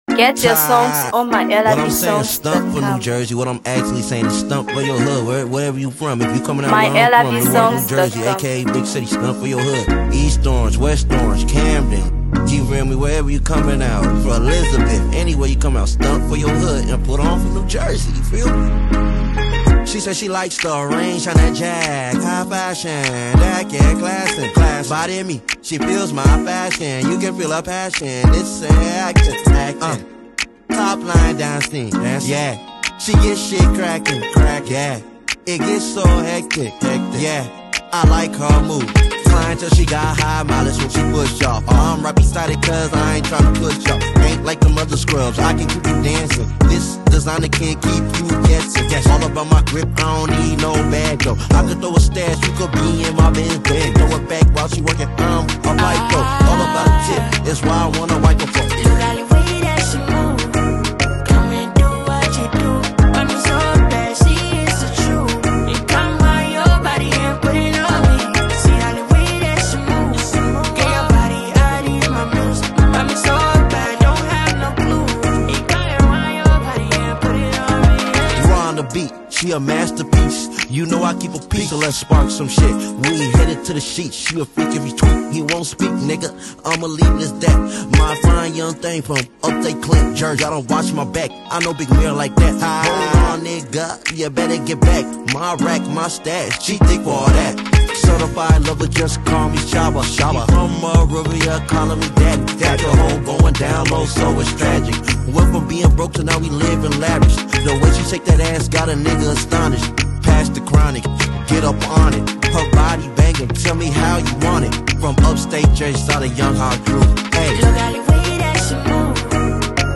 Hip HopMusic